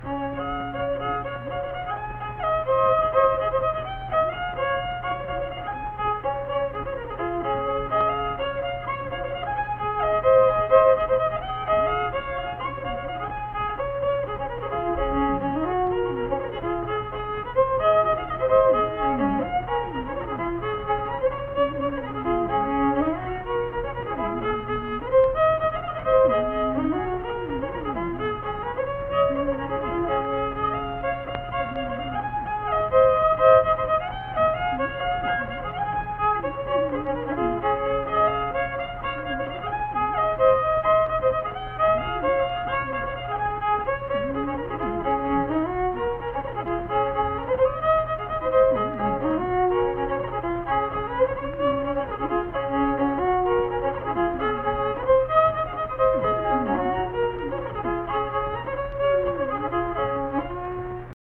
Unaccompanied fiddle music performance
Verse-refrain 2(2).
Instrumental Music
Fiddle